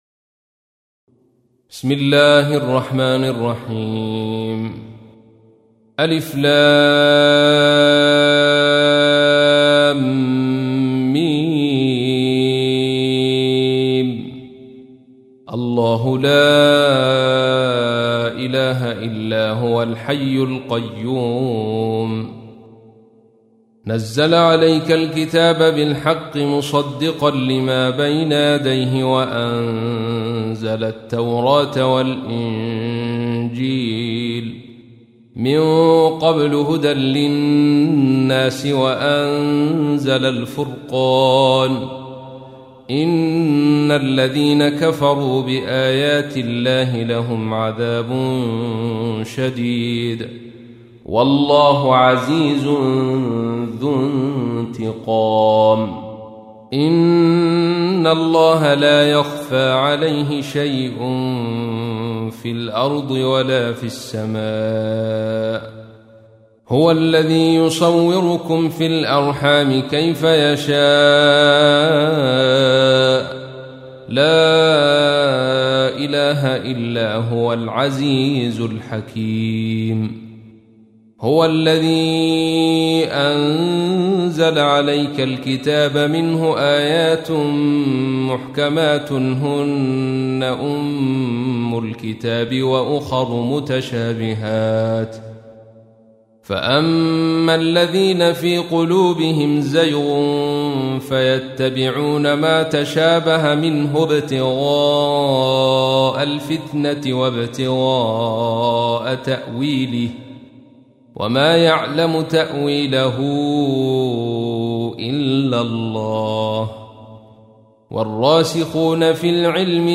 تحميل : 3. سورة آل عمران / القارئ عبد الرشيد صوفي / القرآن الكريم / موقع يا حسين